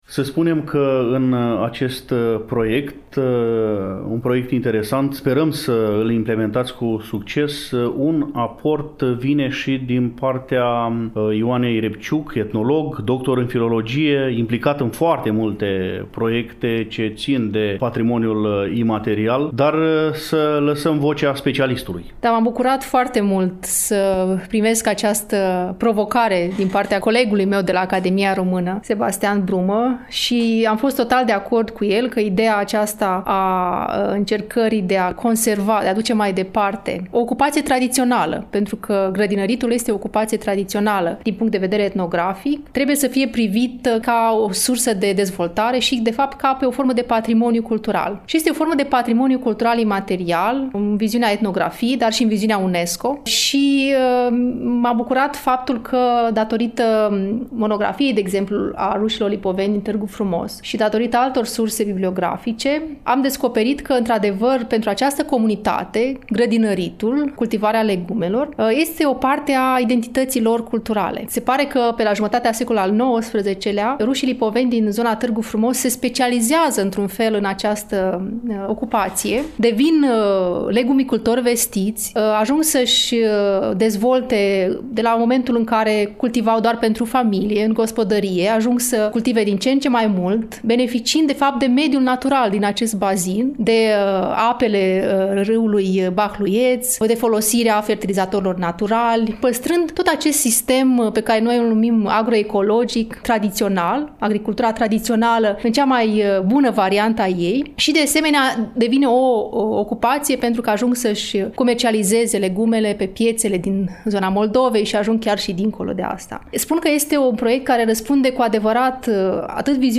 Pentru a afla amănunte concrete atât despre obiectivele proiectului, cât și despre principalele activități din cadrul acestuia, am invitat la dialog pe membrii echipei care se ocupă de implementare.